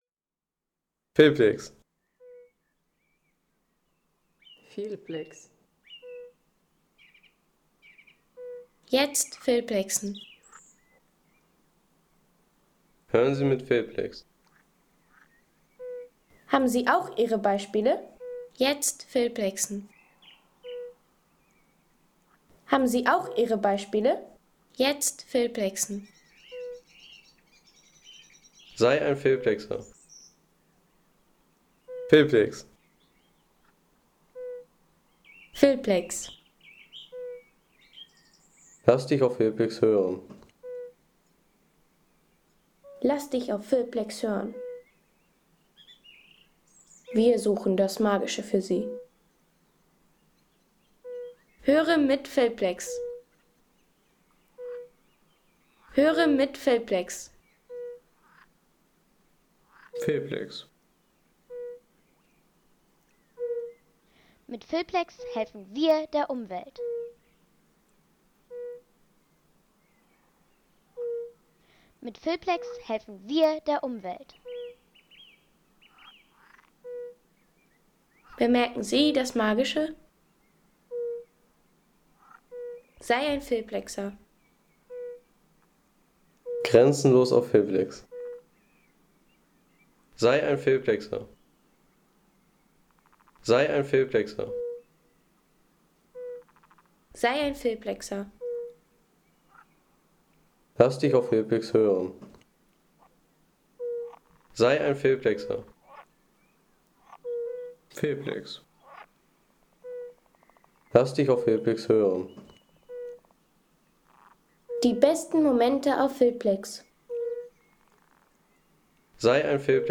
Froschkonzert im Naturschutzgebiet Winderatter See
Tierwelt - Amphibien